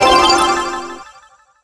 catch_bawl_01.wav